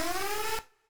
sci-fi_alarm_siren_loop_01.wav